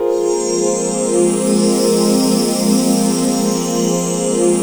FRED PAD 2-R.wav